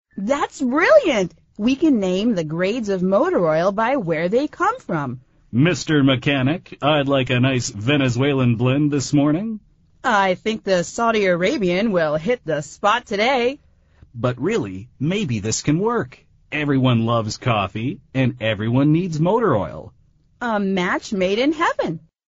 美语会话实录第165期(MP3+文本):A match made in heaven